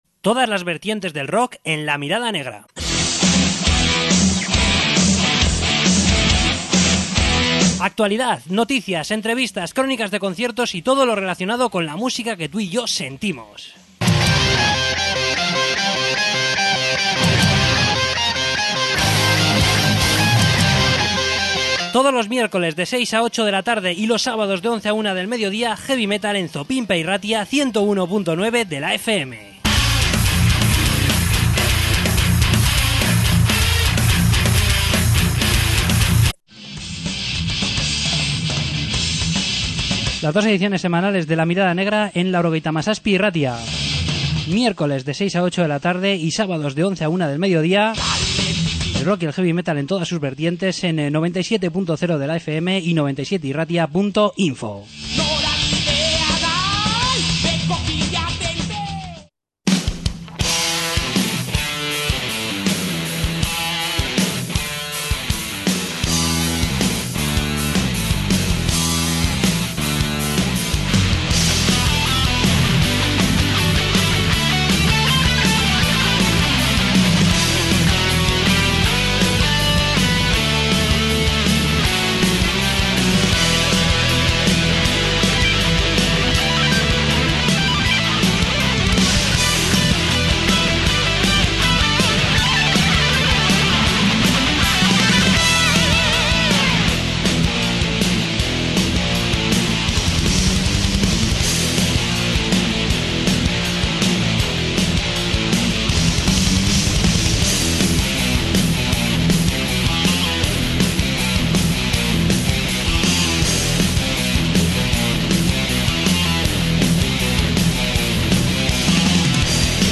Entrevista con Vhaldemar